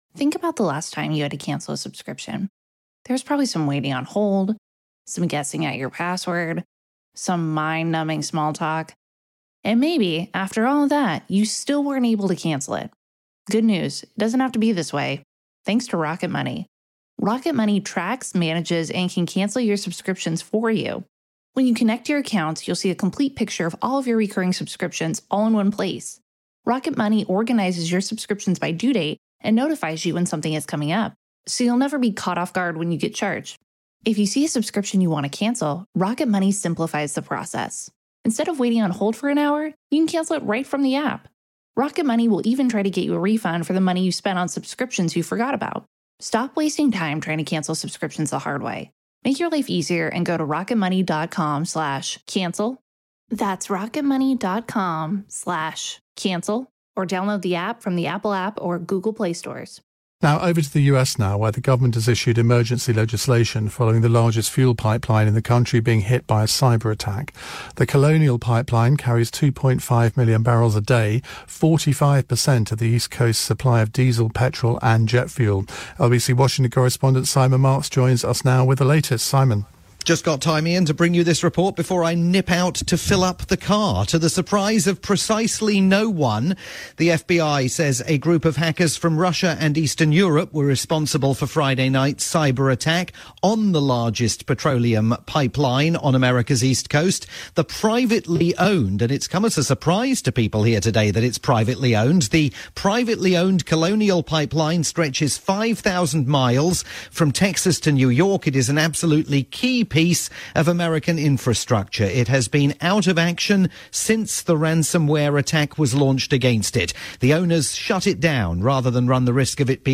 live update